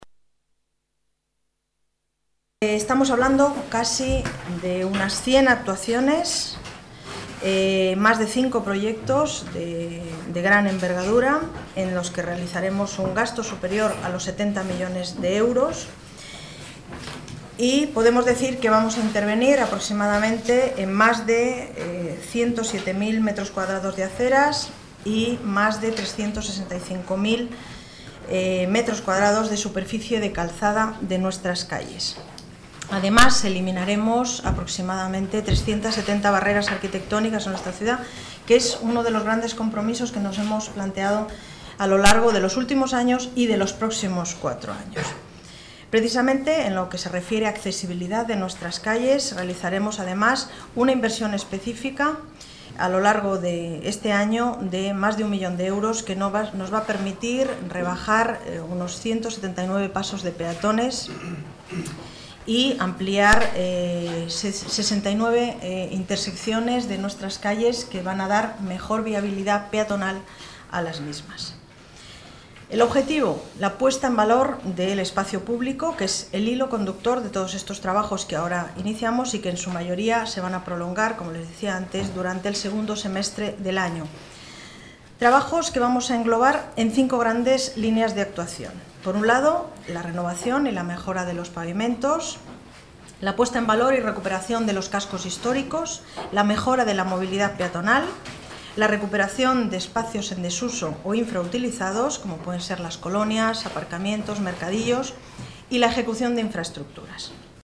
Nueva ventana:Declaraciones delegada de Obras, Paz González: actuaciones 2008 en cifras